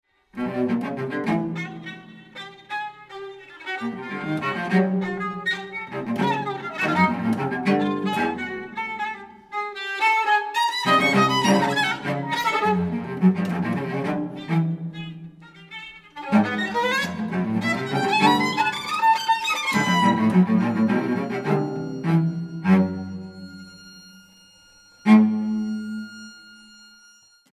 Beschreibung:Kammermusik; Klassik
Besetzung:Violine, Violoncello
Objekt– und Audio–Installation in Dolby Surround